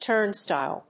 /ˈtɝˌnstaɪl(米国英語), ˈtɜ:ˌnstaɪl(英国英語)/
アクセント・音節túrn・stìle